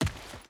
Footsteps
Dirt Run 3.wav